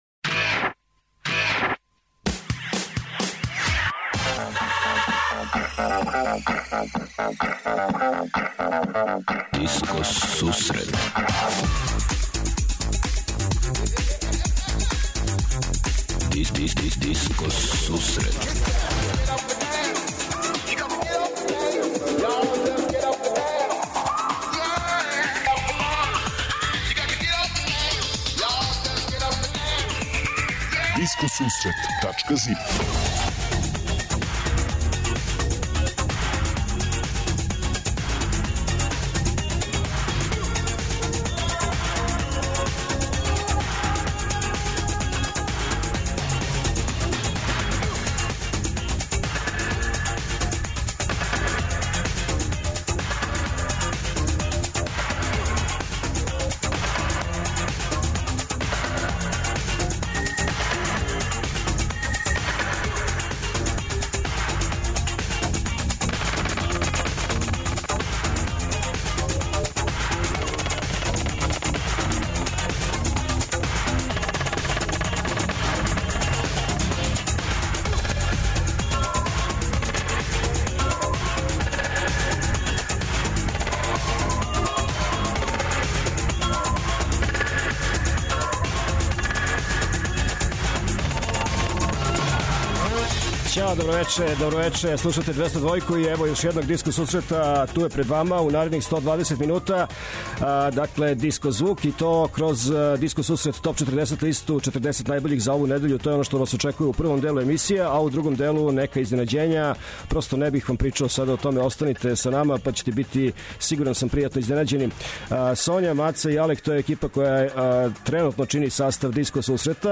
Диско сусрет је емисија посвећена најновијој и оригиналној диско музици у широком смислу, укључујући све стилске утицаје других музичких праваца - фанк, соул, РнБ, итало-диско, денс, поп. Непосредан контакт са слушаоцима уз пуно позитивне енергије је основа на којој ова емисија гради забаву сваке среде.